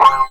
41 GIT03  -L.wav